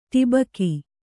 ♪ ṭibaki